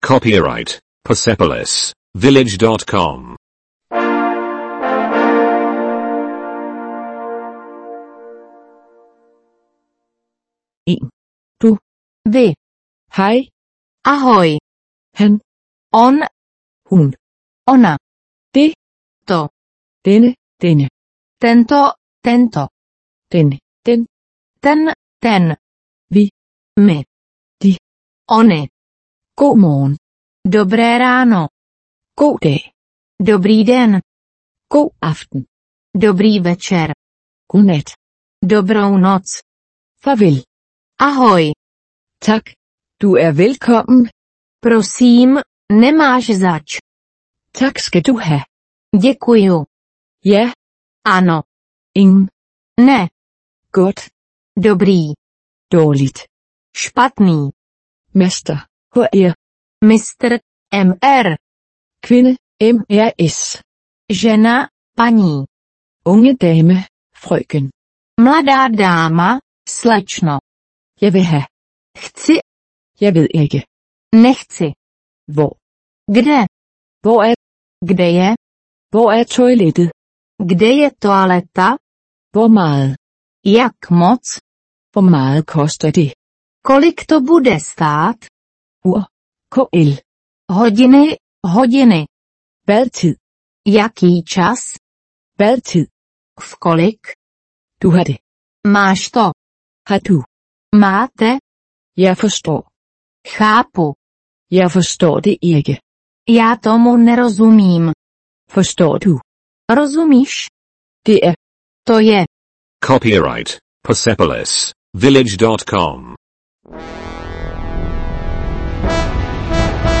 Hvis du ønsker at lære tjekkisk, er du kommet til det rette sted. I disse øvelser vil du høre tjekkiske sætninger, dialoge…